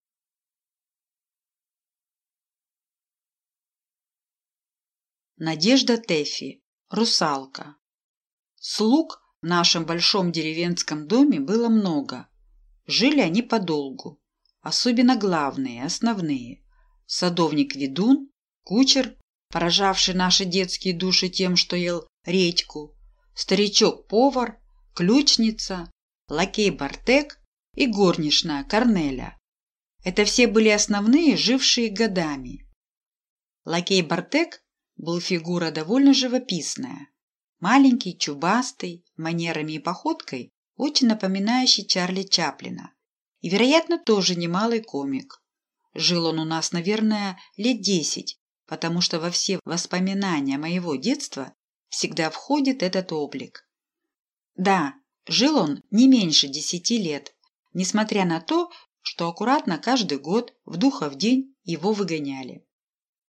Аудиокнига Русалка | Библиотека аудиокниг